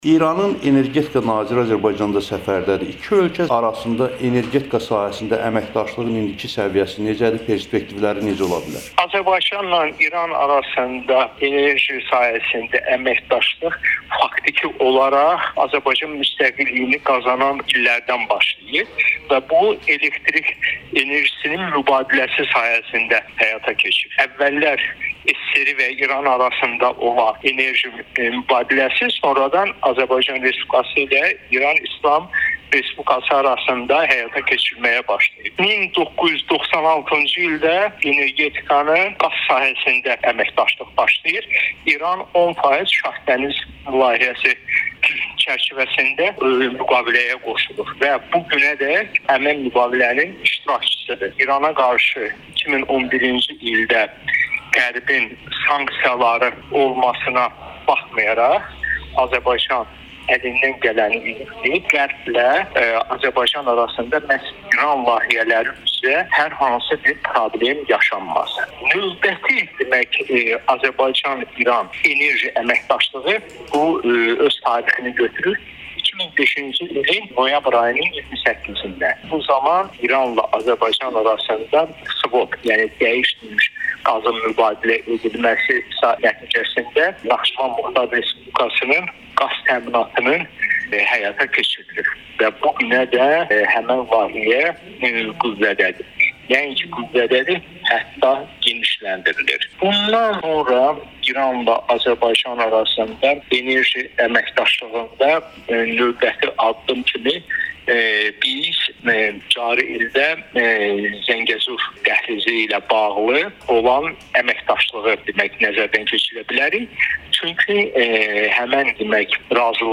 İranın energetika naziri Bakıda səfərdədir - ekspertlə müsahibə